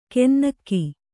♪ kennakki